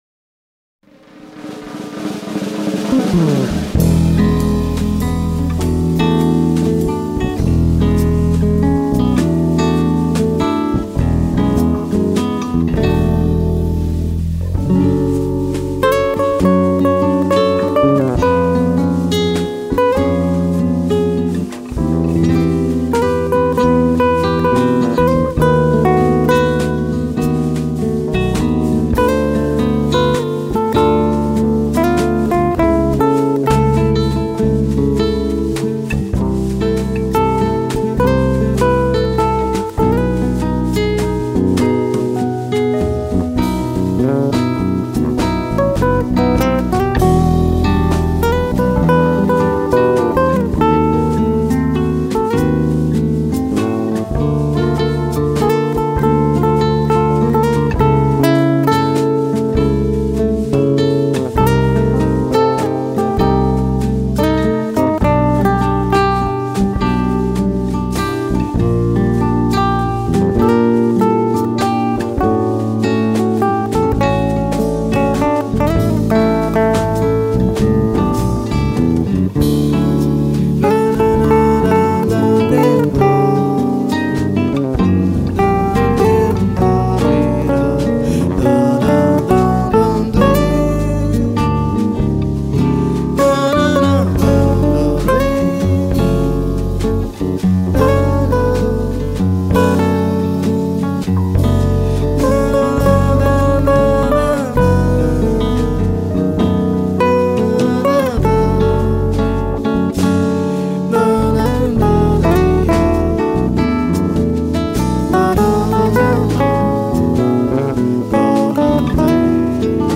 2204   06:11:00   Faixa:     Jazz
Bateria
Teclados
Baixo Acústico, Violao Acústico 6
Saxofone Tenor